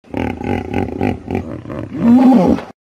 Kuda nil_Suara.ogg